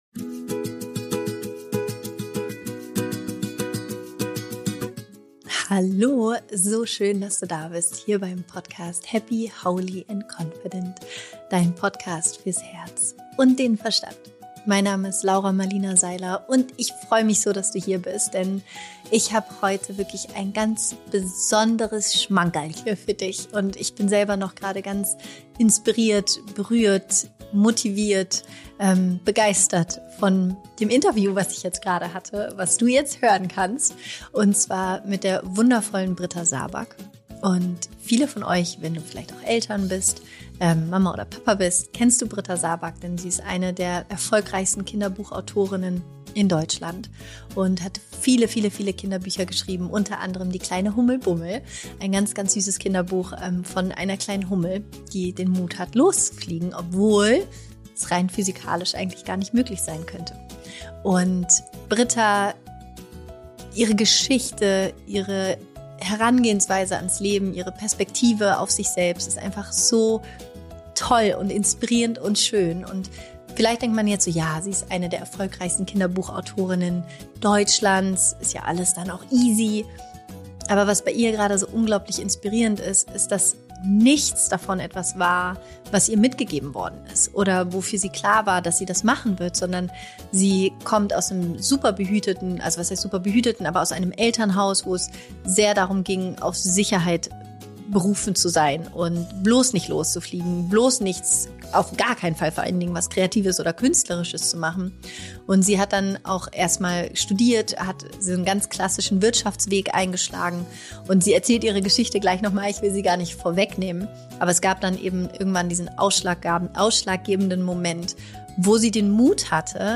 Der eigenen Intuition vertrauen – Interview